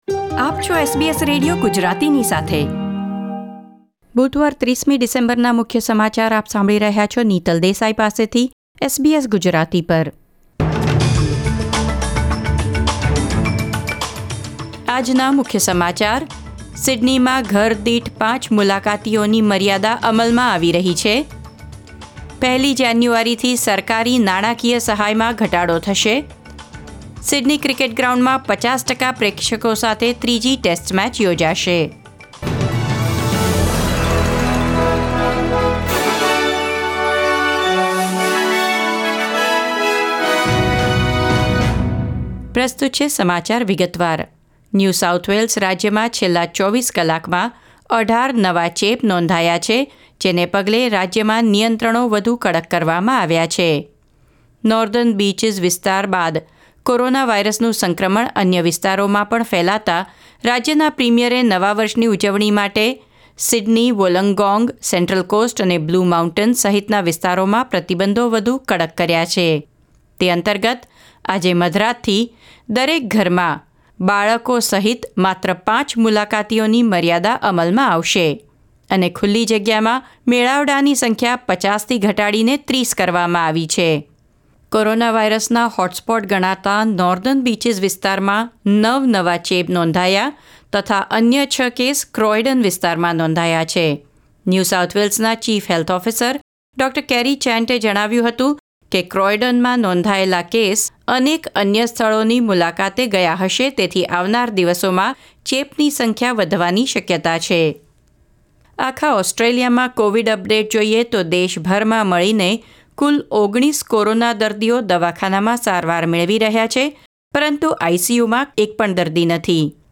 SBS Gujarati News Bulletin 30 December 2020